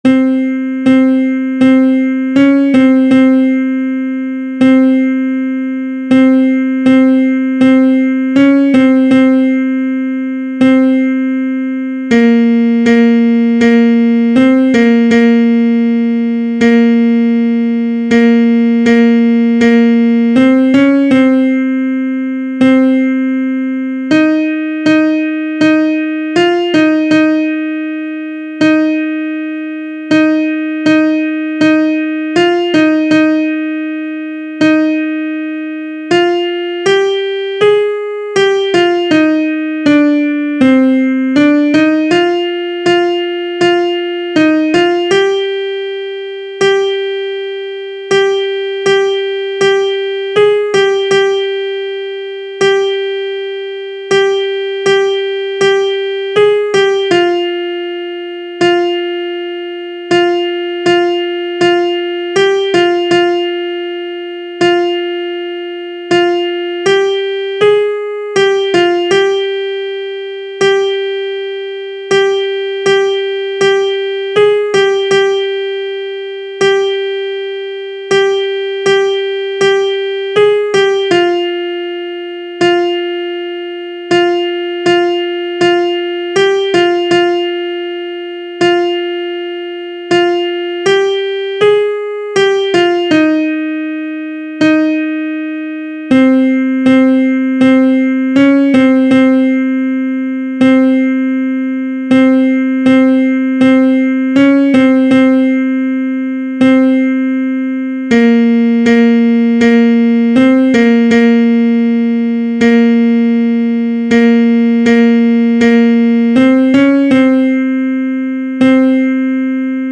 Ténor I